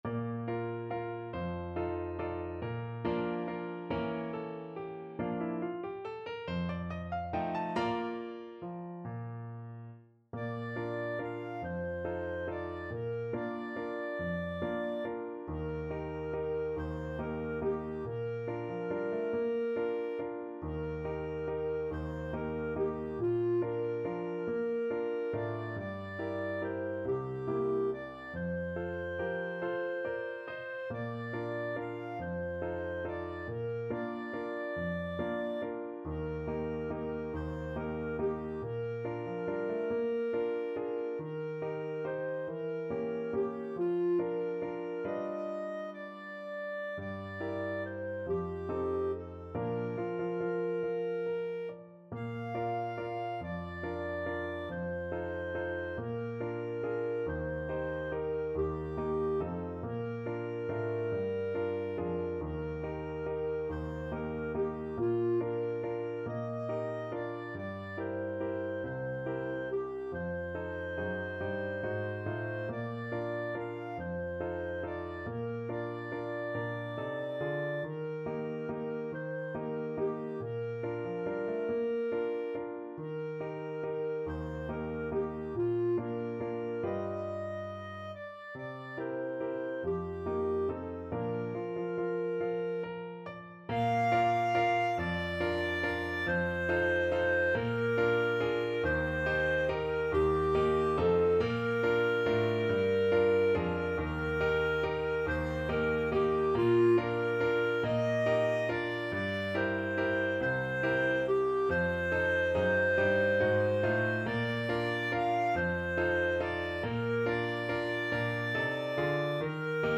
3/4 (View more 3/4 Music)
~ = 140 Tempo di Valse
F5-F6
Pop (View more Pop Clarinet Music)